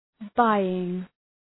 Προφορά
{‘baııŋ}
buying.mp3